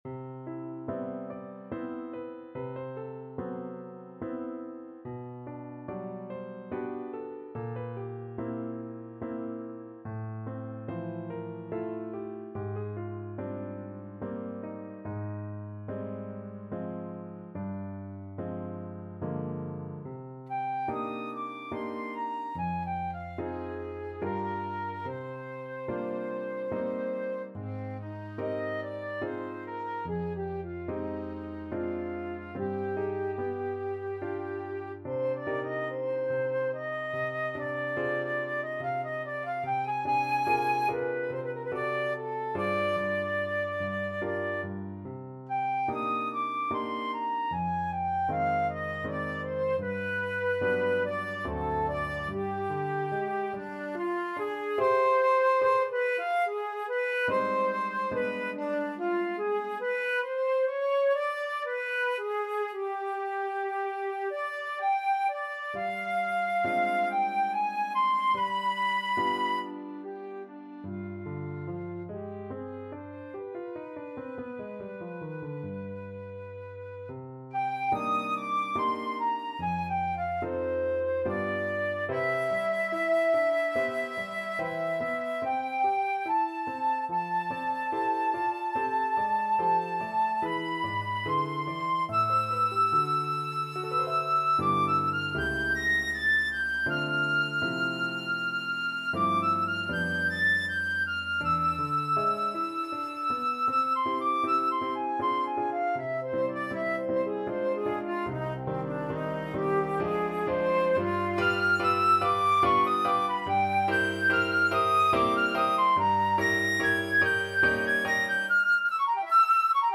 Flute
C minor (Sounding Pitch) (View more C minor Music for Flute )
Andante =72
Classical (View more Classical Flute Music)